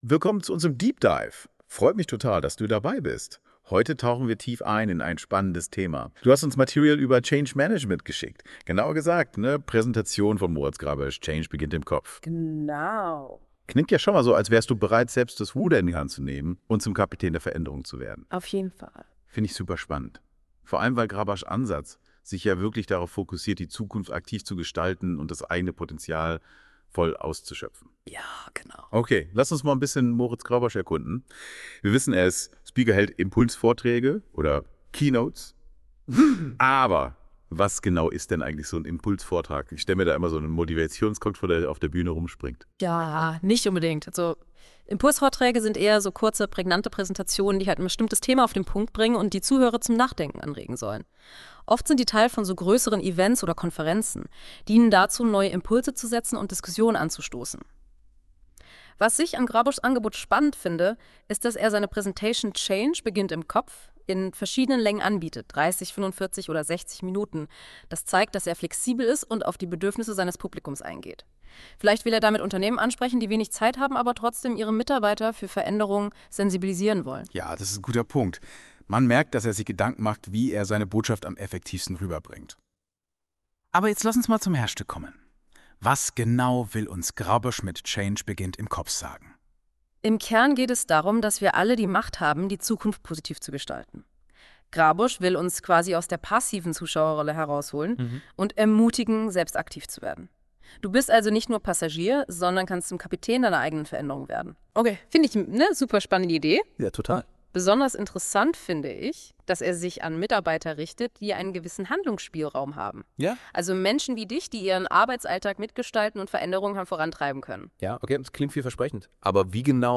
von Künstliche Intelligenz | Change beginnt im Kopf